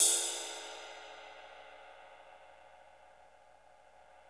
CYM XRIDE 5C.wav